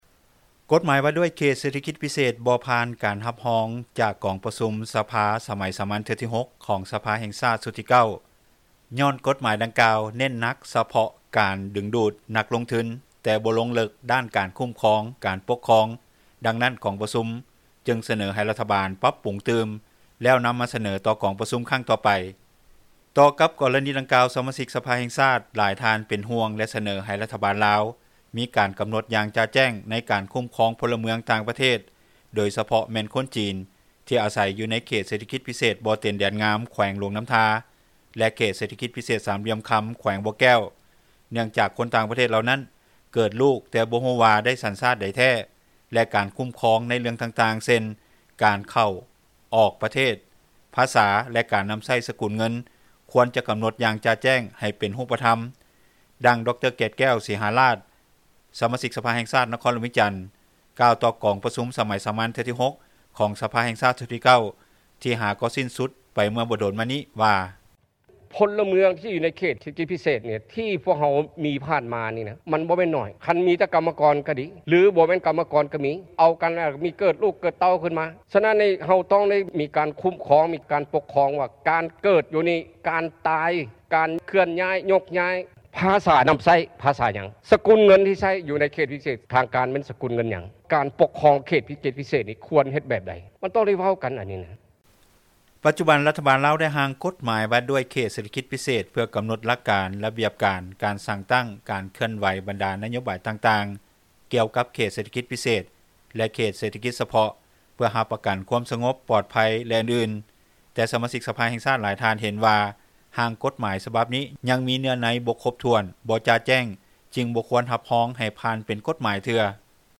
ດັ່ງ ດຣ. ເກດແກ້ວ ສີຫາລາດ ສະມາຊິກສະພາແຫ່ງຊາຕ ນະຄອນຫຼວງວຽງຈັນ ກ່າວຕໍ່ກອງປະຊຸມ ສໄມສາມັນ ເທື່ອທີ 6 ຂອງສະພາແຫ່ງຊາຕຊຸດທີ 9 ທີ່ຫາກໍສິ້ນສຸດ ໄປເມື່ອບໍ່ດົນມານີ້ວ່າ: